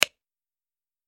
دانلود آهنگ کلیک 47 از افکت صوتی اشیاء
جلوه های صوتی